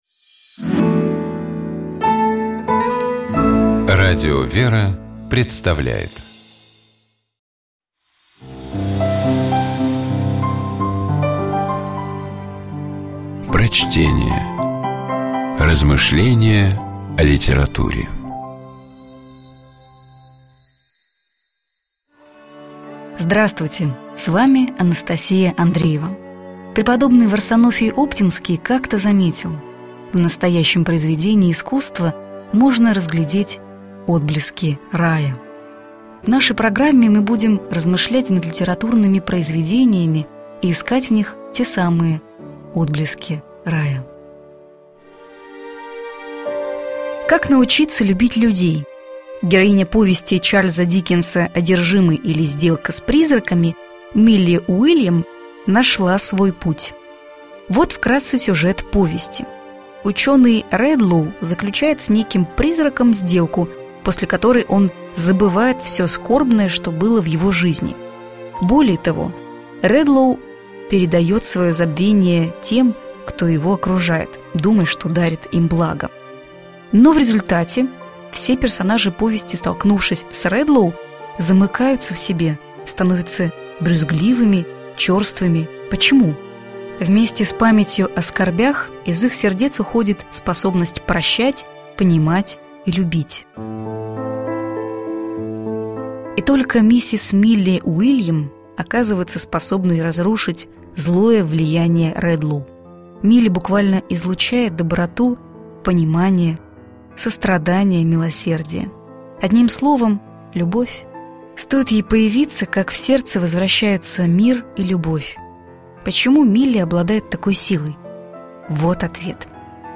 Prochtenie-Ch_-Dikkens_-Oderzhimyj-ili-sdelka-s-prizrakom-Kak-nauchitsja-ljubit-ljudej.mp3